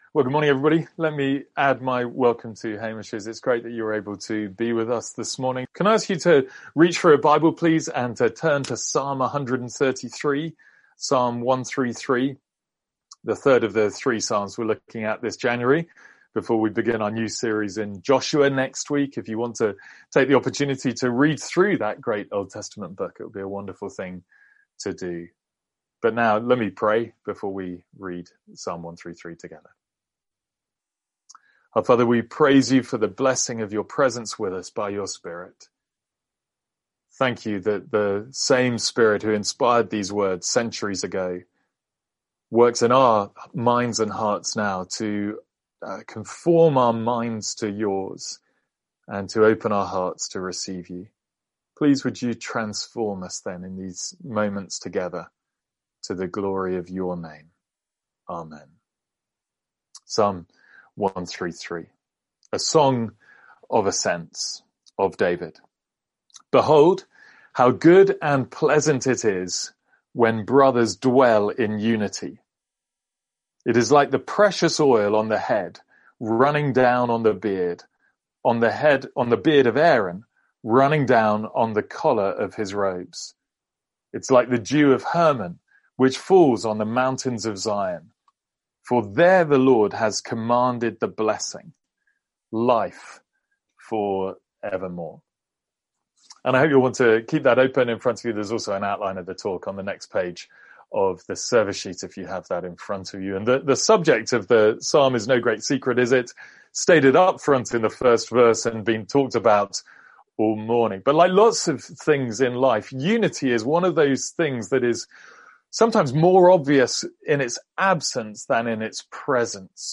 A one off sermon in Psalm 133.